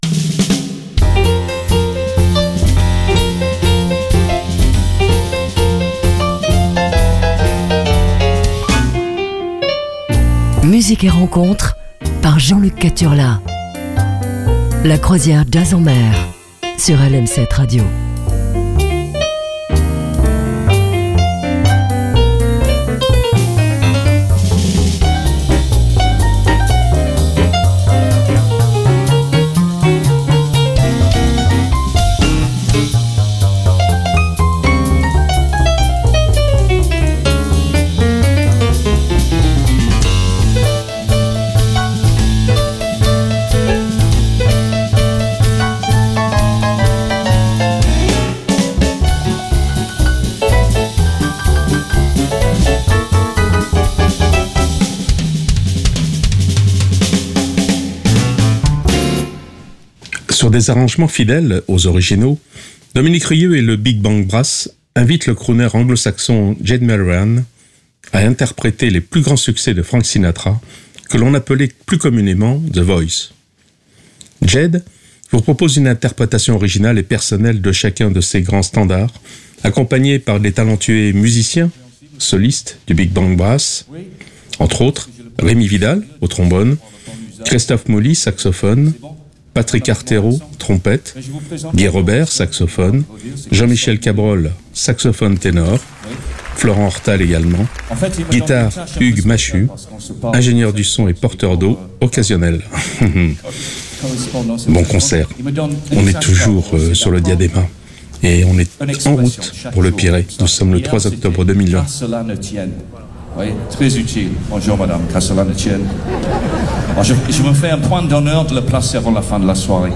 sur des rythmes jazzy